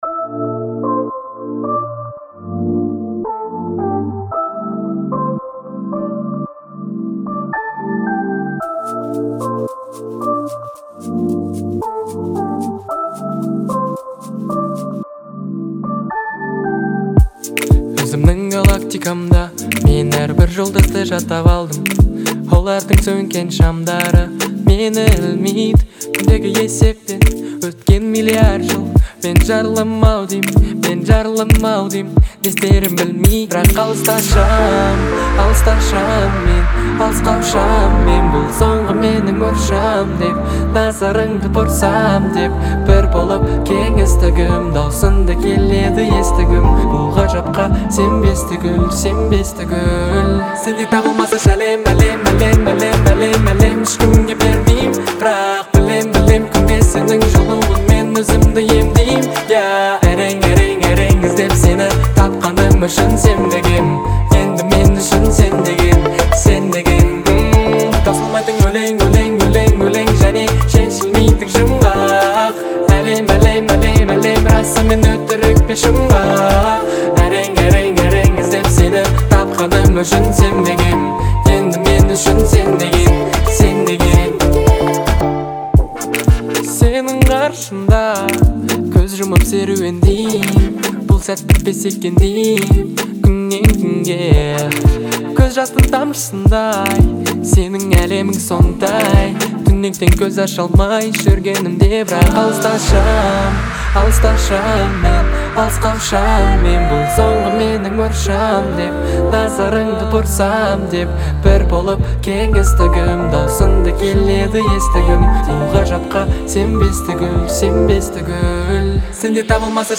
это завораживающий трек в жанре R&B